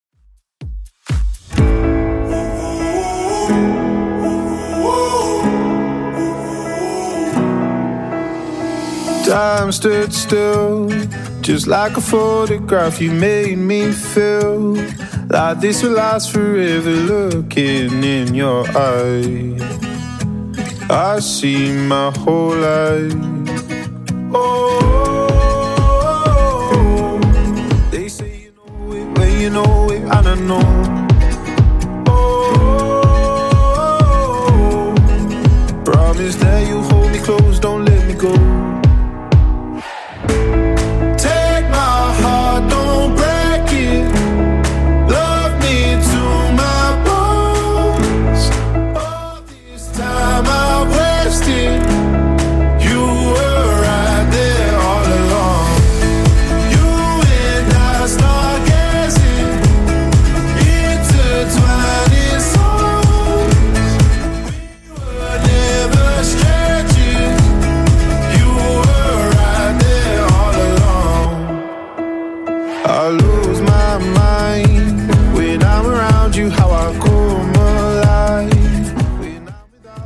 BPM: 124 Time